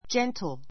gentle A2 dʒéntl ヂェ ン トる 形容詞 ❶ （人について） 穏 おだ やかな, おとなしい, 優 やさ しい a gentle heart a gentle heart 優しい心 a gentle manner a gentle manner 上品な態度 Please be gentle with the doll; it breaks easily.